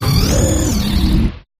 stonjourner_ambient.ogg